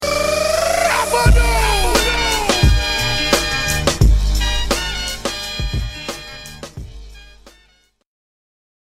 follower alert sound effects
follower-alert